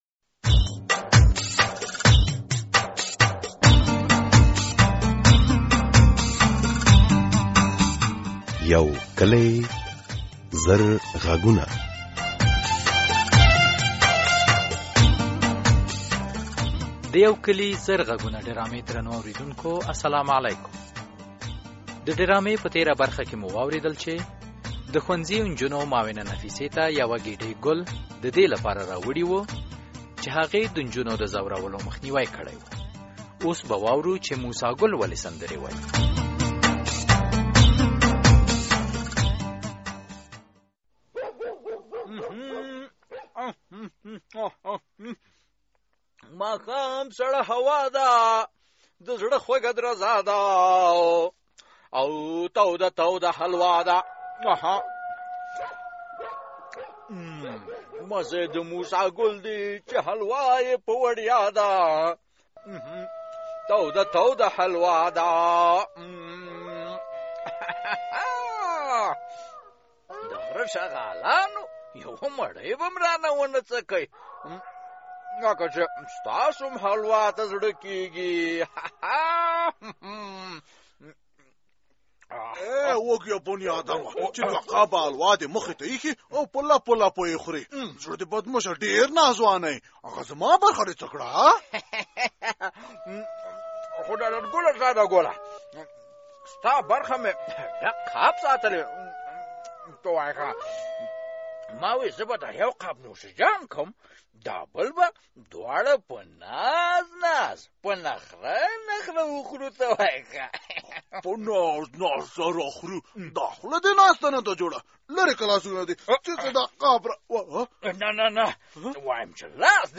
د افسرې مرسل په هڅو د ماشومانو تښتوونکې ډله دا وخت په زندان کې ده. د یو کلي زر غږونو په ډرامه کې ...